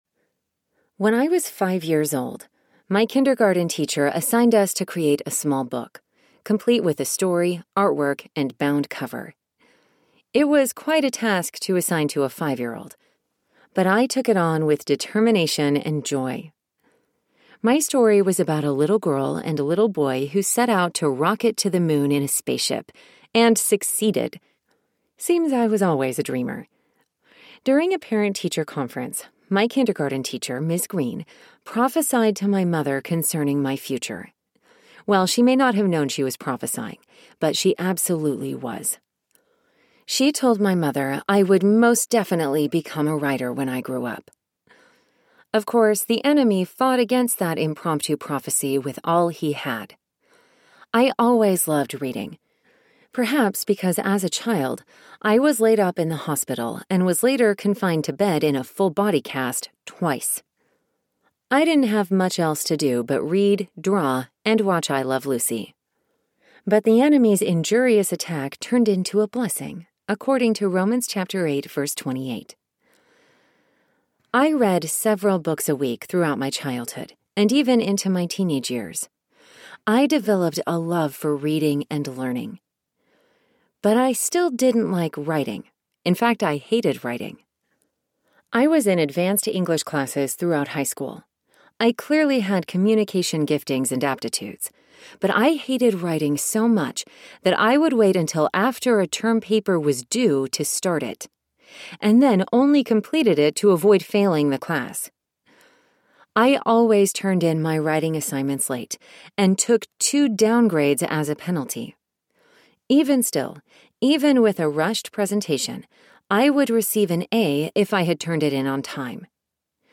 Walking in Your Prophetic Destiny Audiobook
Narrator
5.2 Hrs. – Unabridged